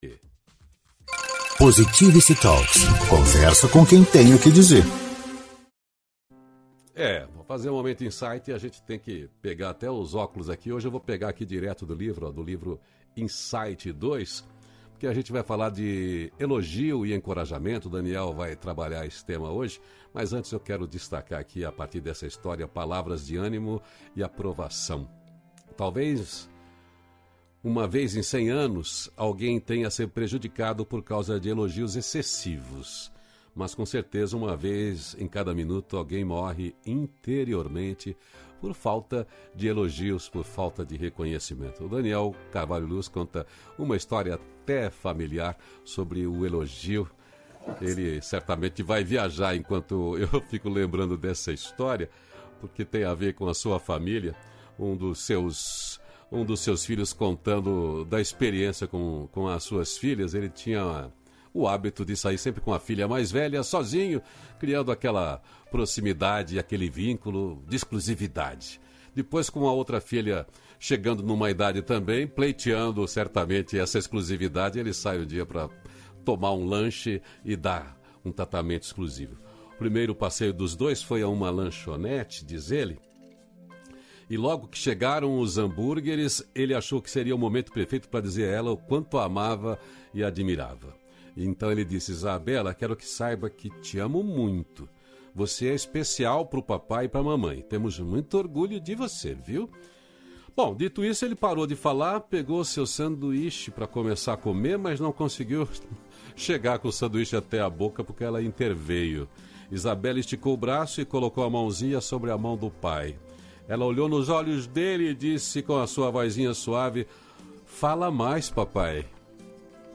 Elogio e encorajamento – Momento Insight Ao Vivo